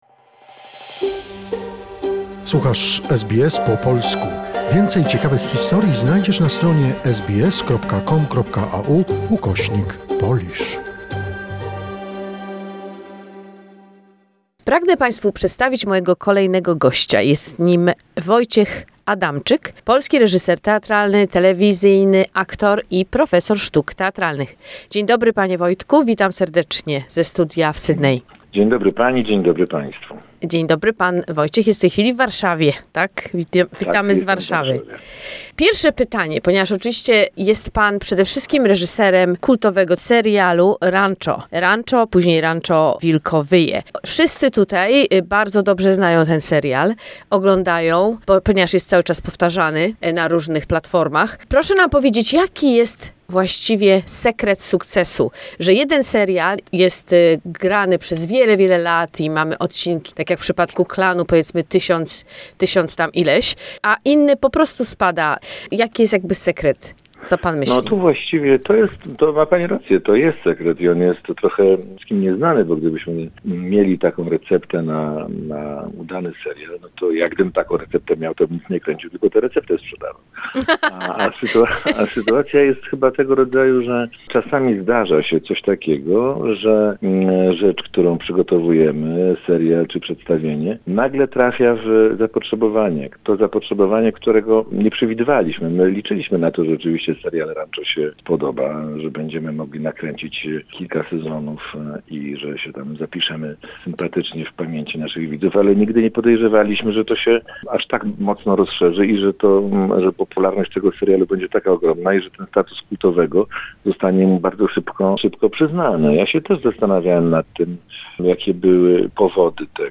Rozmowa z twórcą kultowego serialu "Ranczo" Wojciechem Adamczykiem - część 1